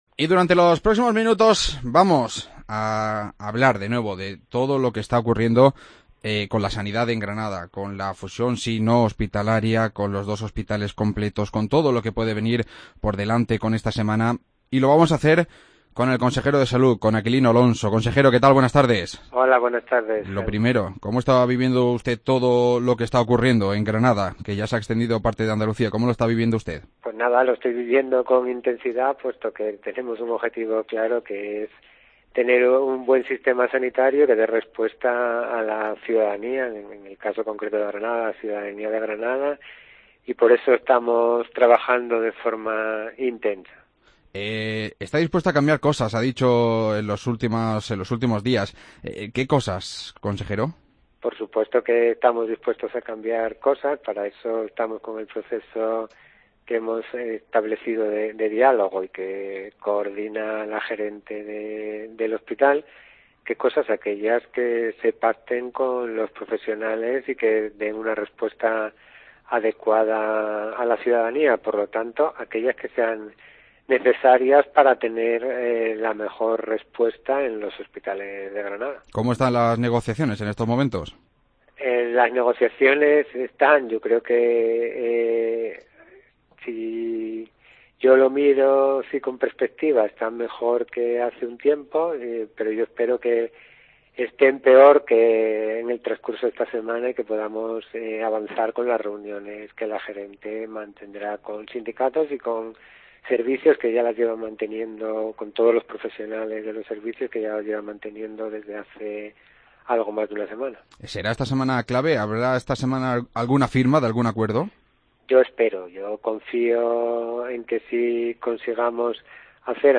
Entrevista a Aquilino Alonso, consejero de Salud